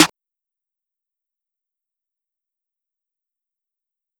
snare 1.wav